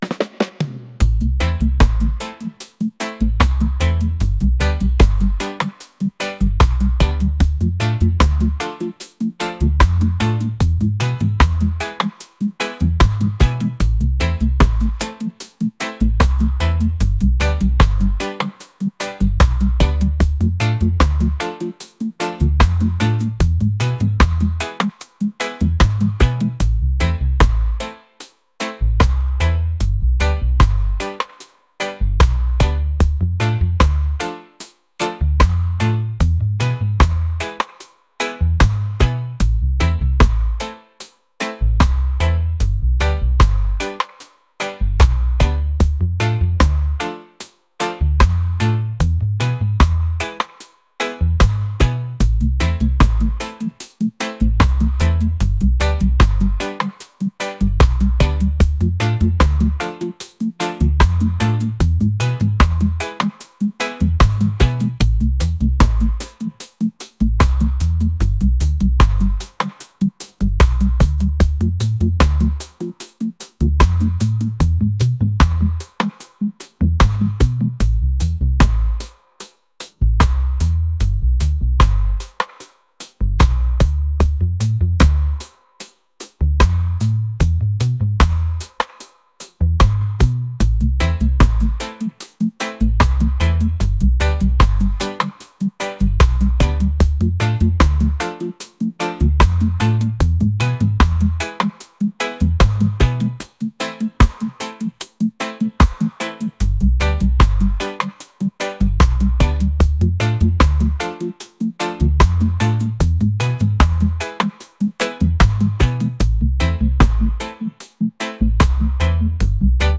reggae | soul & rnb | pop